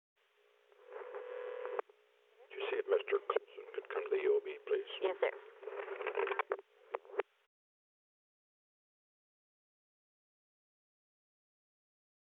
Conversation: 034-139
Recording Device: White House Telephone
On December 20, 1972, President Richard M. Nixon and the White House operator talked on the telephone at 12:29 pm. The White House Telephone taping system captured this recording, which is known as Conversation 034-139 of the White House Tapes.